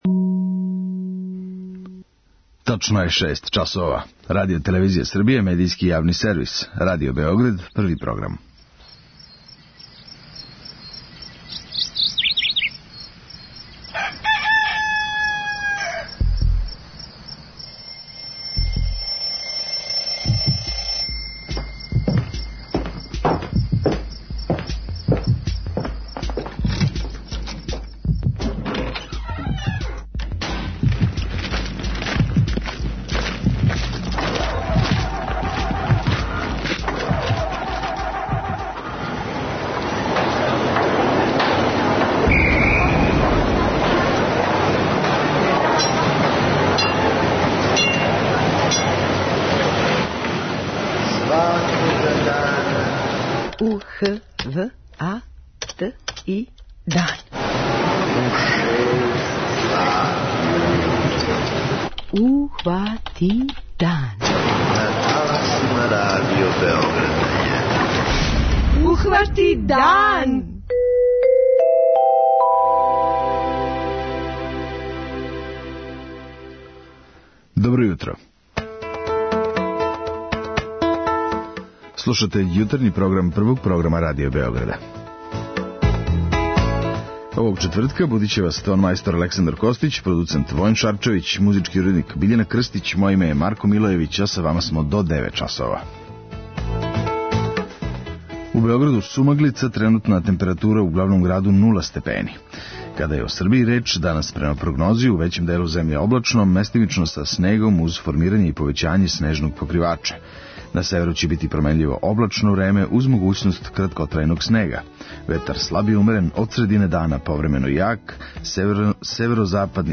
У оквиру емисије емитујемо: 06:03 Јутарњи дневник; 06:35 Догодило се на данашњи дан; 07:00 Вести; 07:05 Добро јутро децо; 08:00 Вести; 08:10 Српски на српском
О начину и условима пријављивања, овога јутра разговарамо са Ренатом Пинџо, помоћницом министра у Министарству туризма, трговине и телекомуникација.